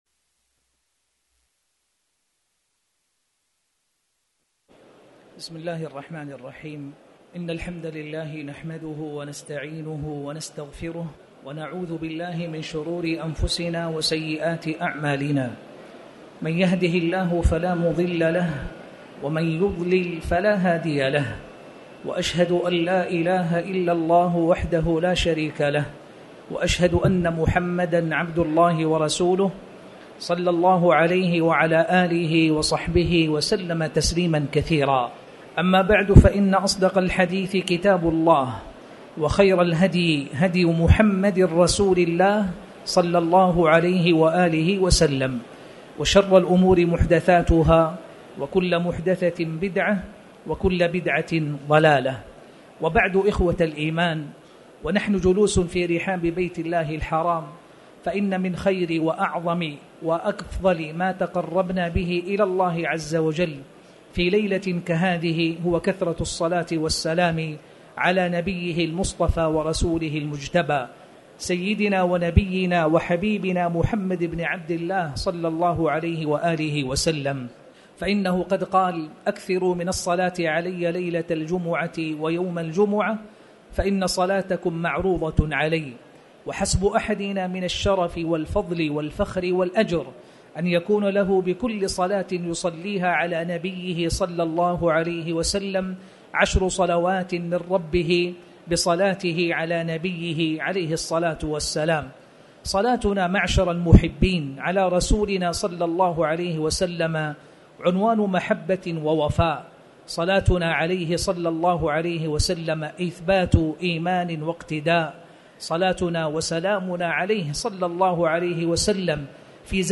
تاريخ النشر ١٩ رجب ١٤٣٩ هـ المكان: المسجد الحرام الشيخ